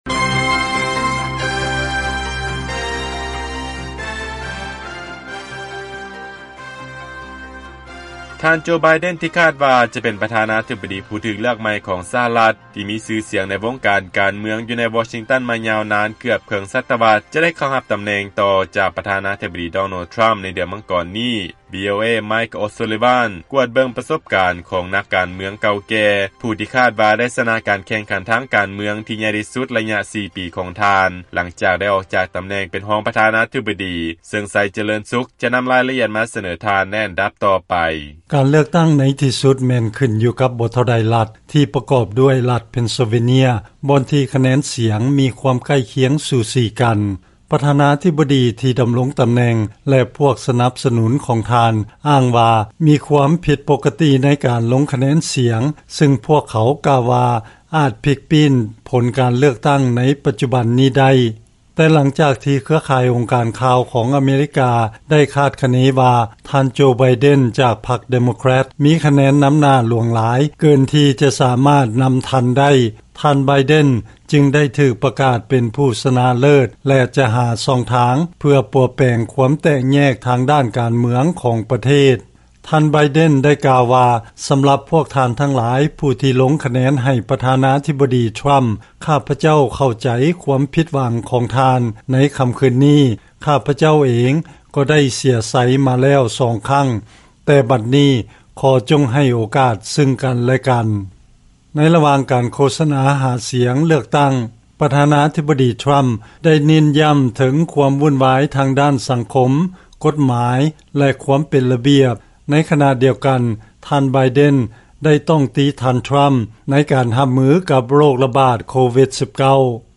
ເຊີນຟັງລາຍງານ ທ. ໄບເດັນ ຜູ້ທີ່ຄາດວ່າ ໄດ້ຖືກເລືອກ ໃຫ້ເປັນປະທານາທິບໍດີ ຫຼັງຈາກຢູ່ໃນວົງການການເມືອງ ມາໄດ້ເກືອບເຄິ່ງສັດຕະວັດ